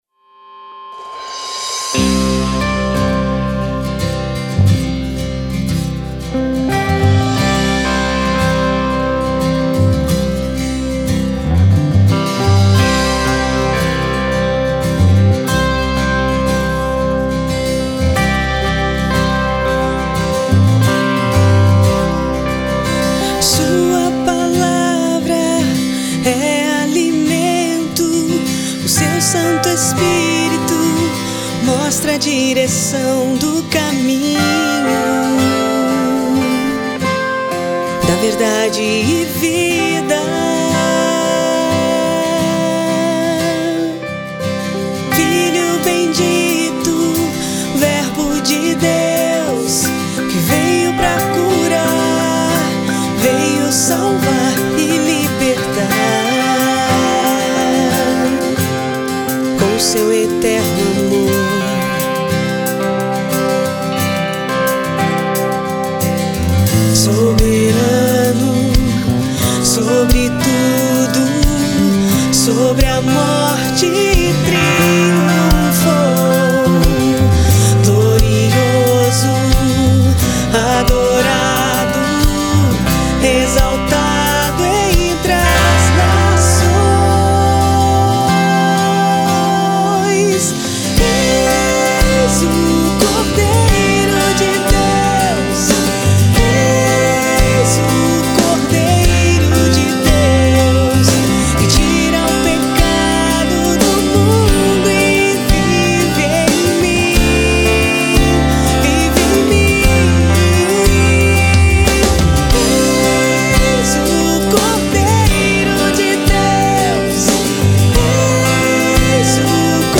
é um CD que mescla folk, pop e rock contemporâneo